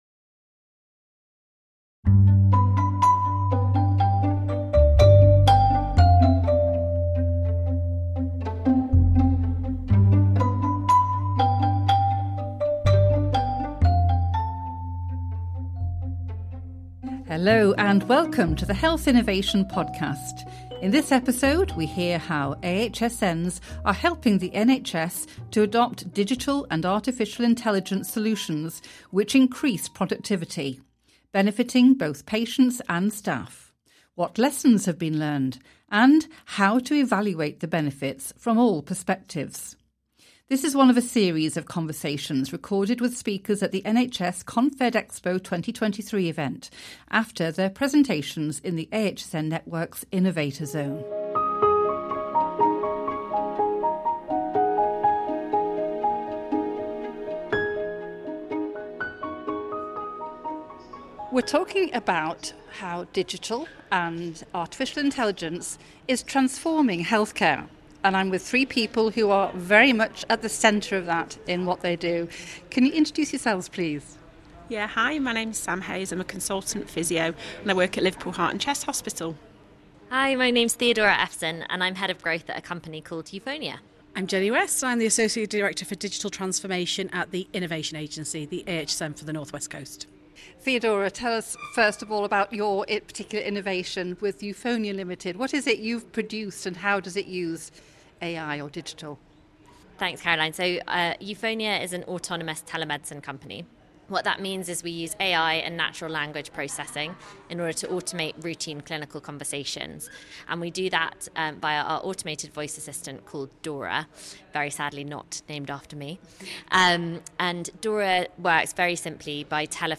The episode was recorded at NHS ConfedExpo 2023 following a session in the Innovator Zone Theatre.